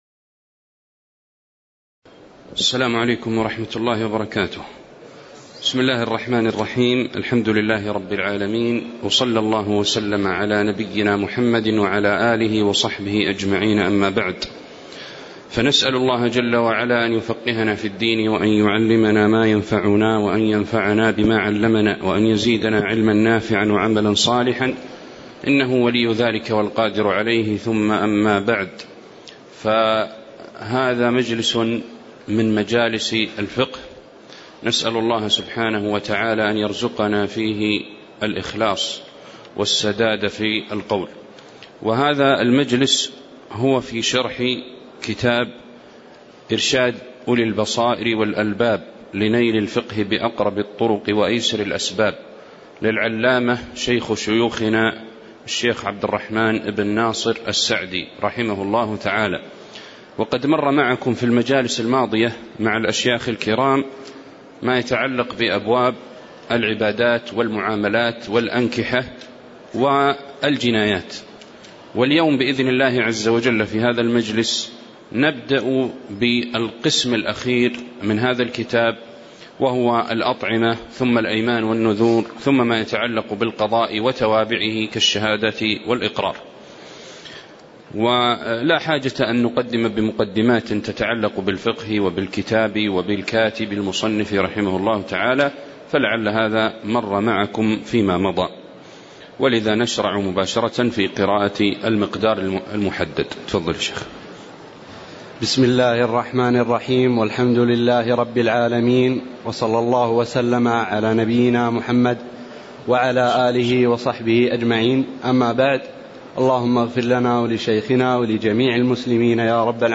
تاريخ النشر ٢٣ شوال ١٤٣٨ هـ المكان: المسجد النبوي الشيخ